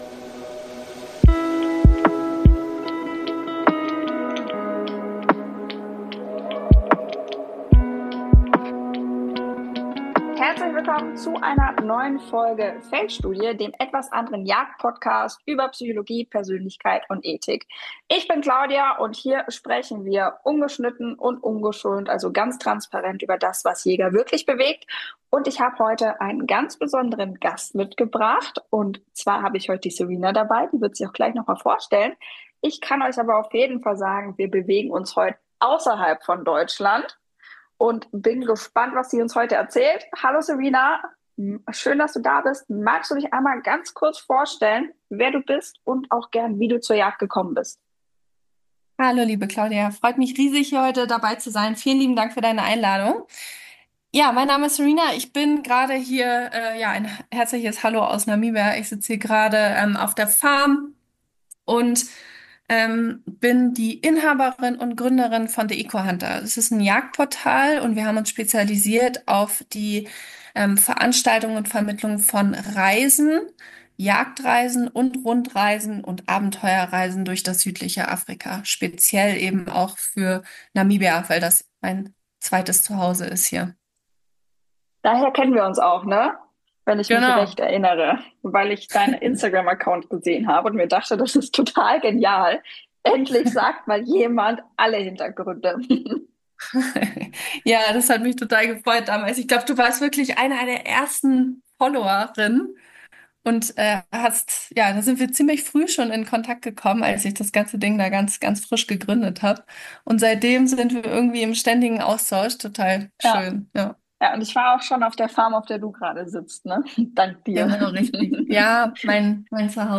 Hinweis zur Tonqualität: Zu Beginn der Folge gab es technische Schwierigkeiten mit meiner Tonspur – meine Stimme klingt anfangs nicht ganz optimal, wird aber im Verlauf besser.